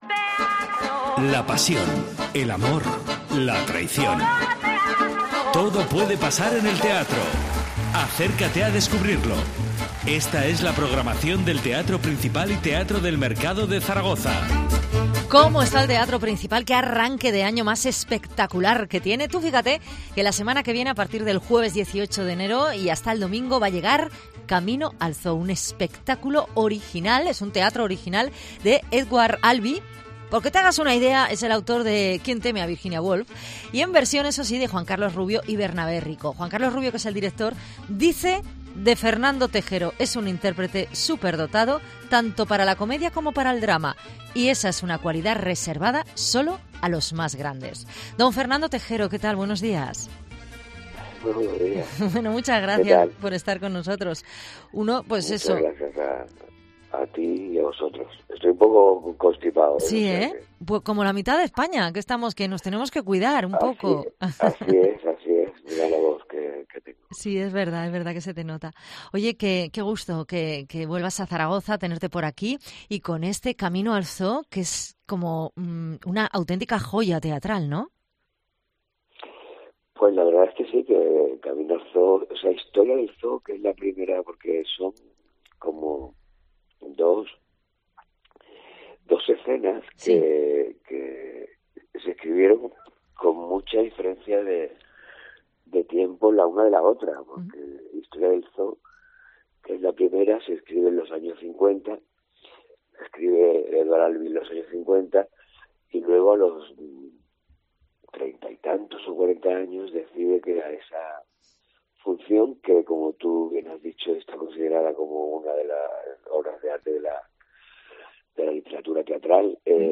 Entrevista al actor Fernando Tejero que estrena en el Teatro Principal 'Camino al zoo'